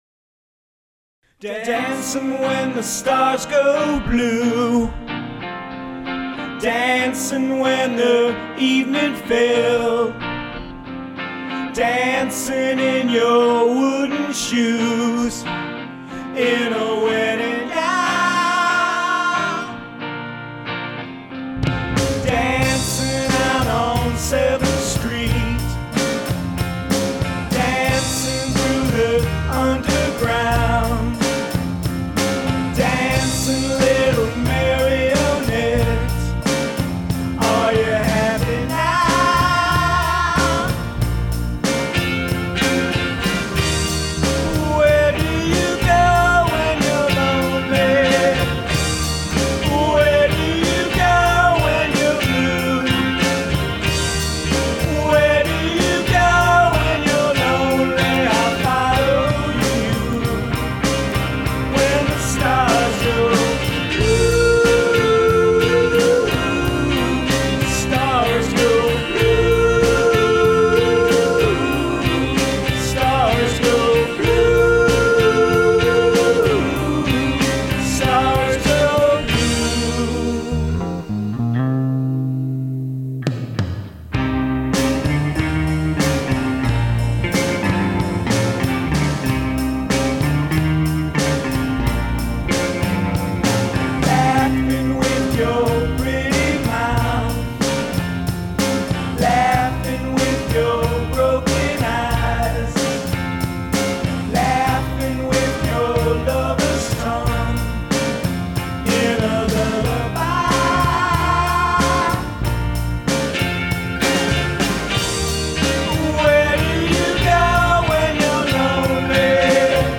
Drums
and Vocals
Guitar
Bass